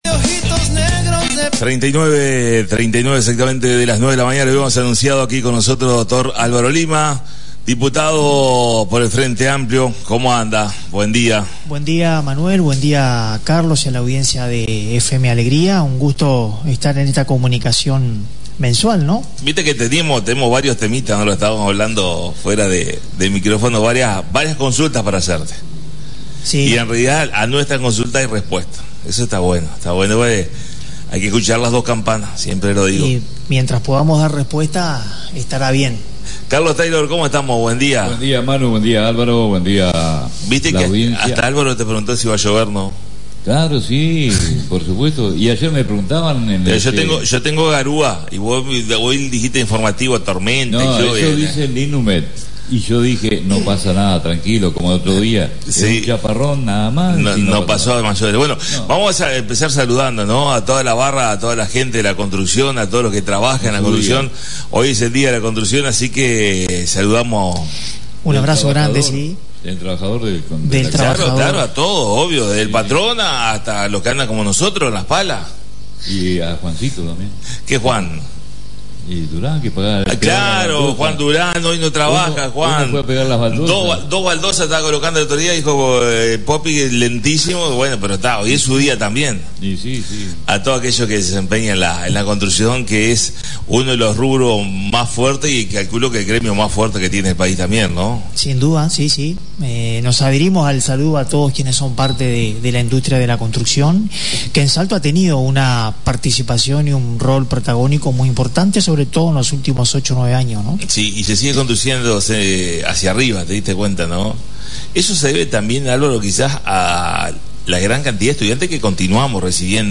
Una puesta a punto y muchas novedades con la visita a la Radio del Dr Álvaro Lima Diputado por el Frente Amplio